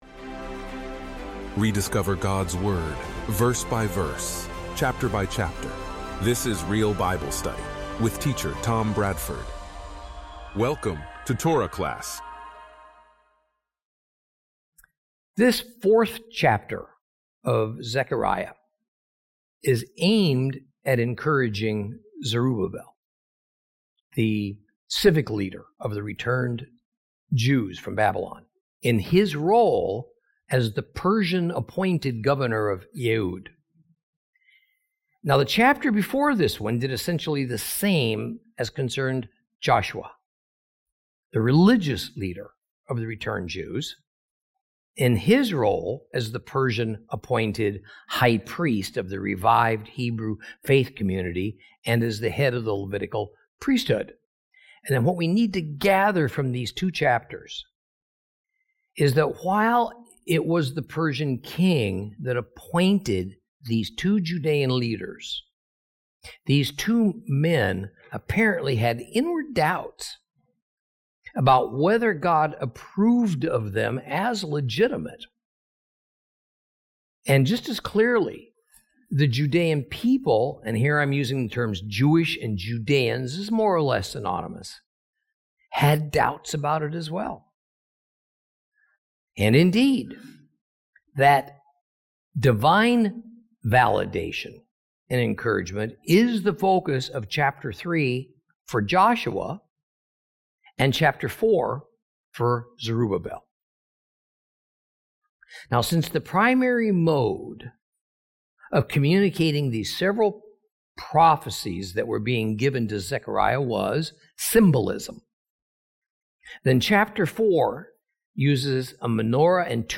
Teaching from the book of Zechariah, Lesson 9 Chapters 4 & 5.